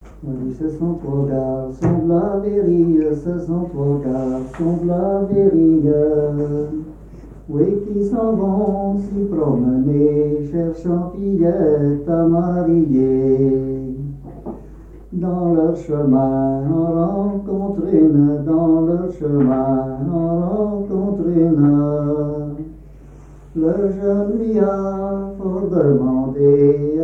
Musique à danser, rondes chantées et monologue
Pièce musicale inédite